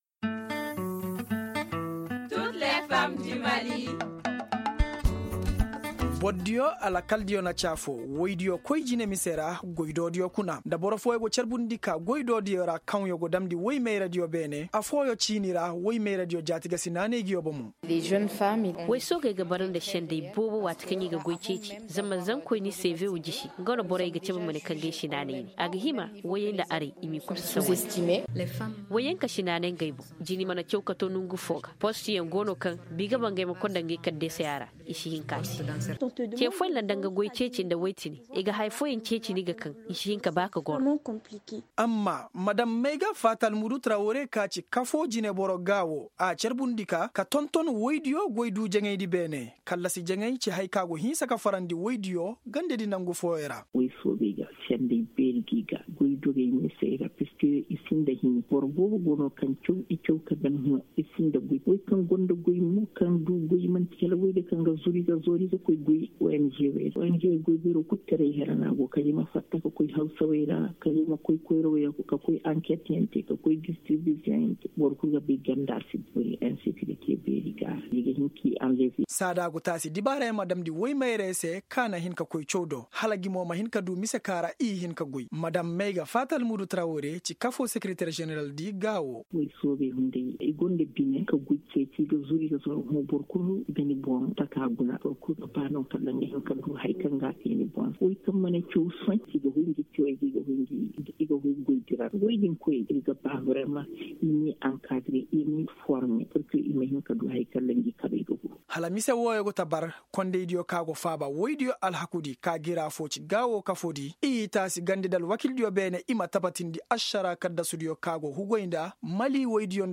Le Magazine « Toutes les femmes du Mali » est présenté par